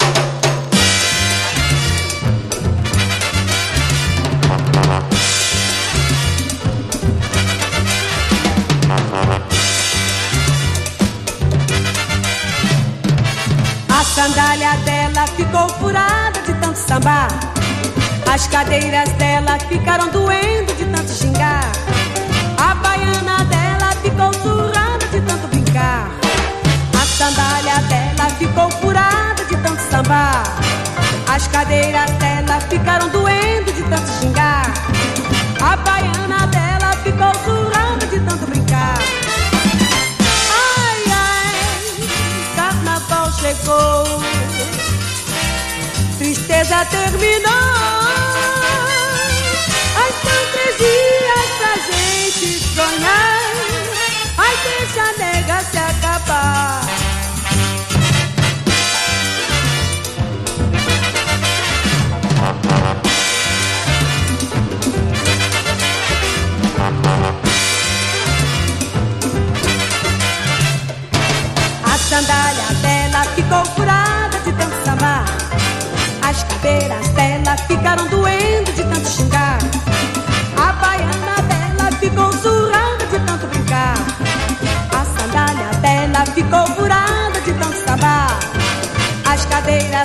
スレあるため試聴でご確認ください